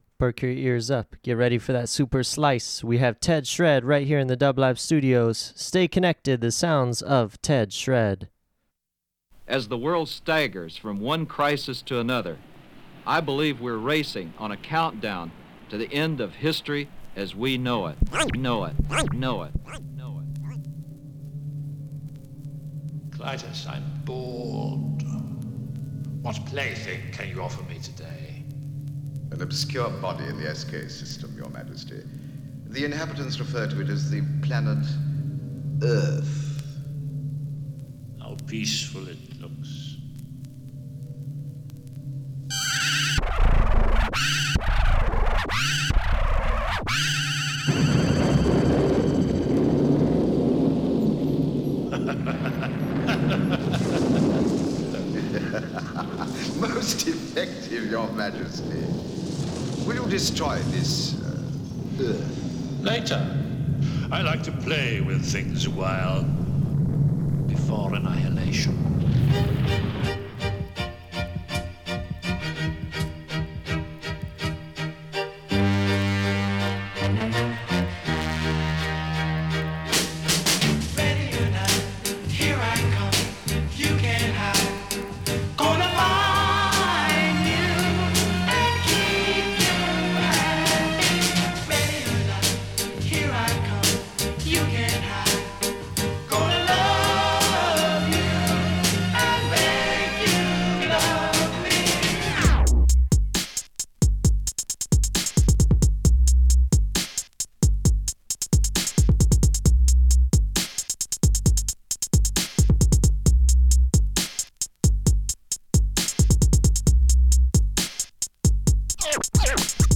Dance Hip Hop Rap Reggae/Dub Rock